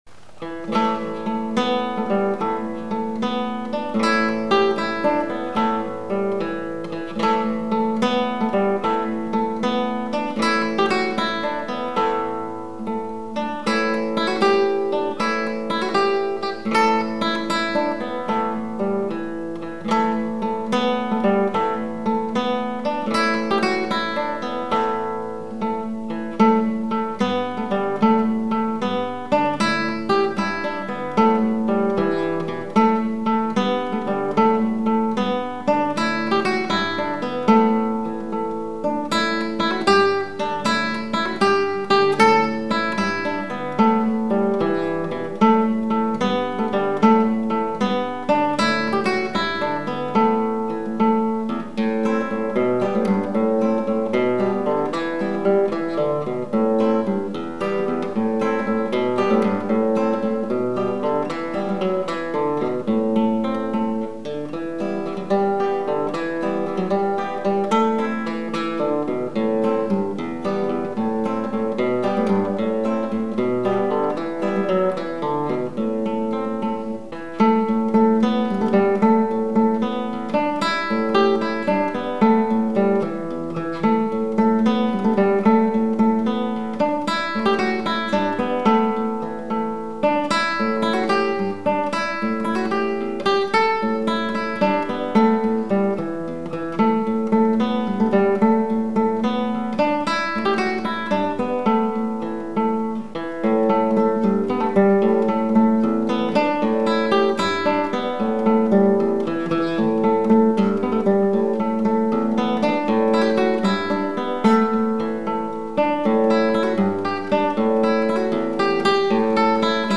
Southern Shape-note Hymnal, Guitar Solo
DIGITAL SHEET MUSIC - FINGERPICKING SOLO